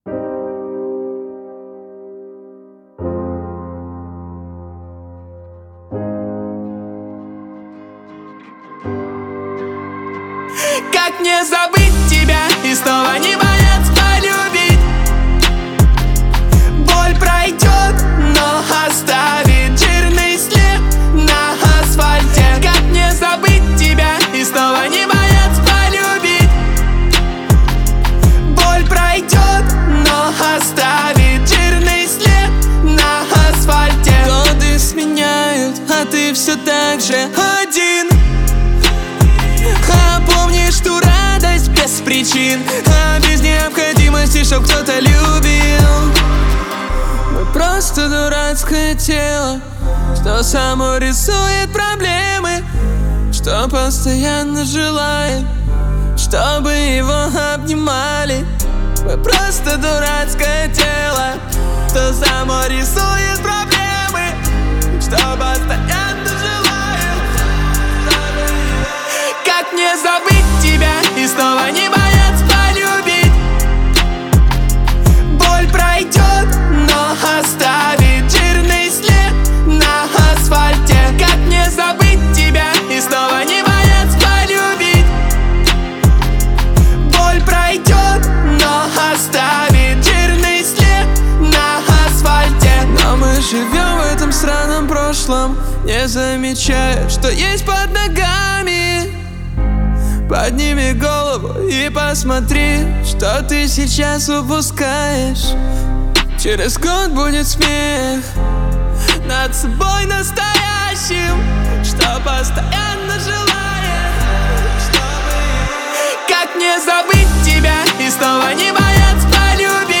это глубокая и эмоциональная баллада в жанре поп-рок.